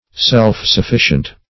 Self-sufficient \Self`-suf*fi"cient\, a.